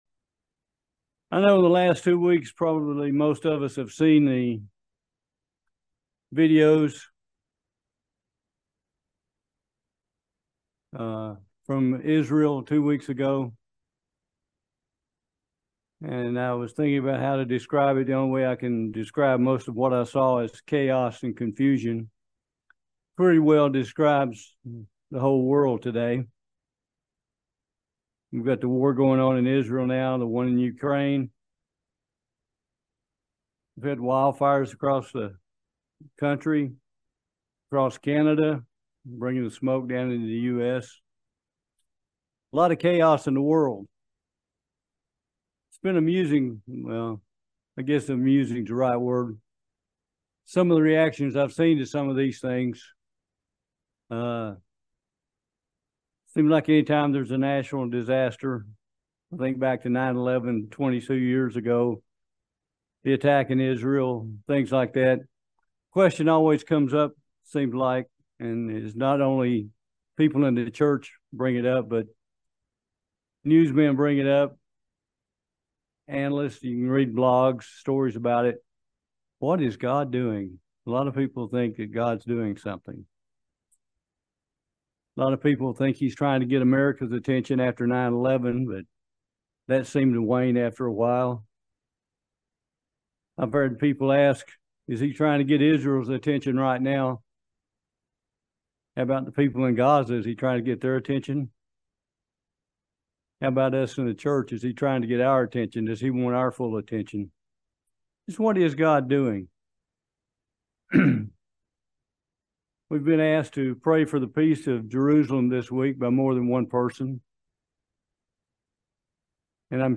Join us for this video sermon on the subject of Chaos and Confusion. 3 points to help us discern Satanic chaos and confusion.
Given in Lexington, KY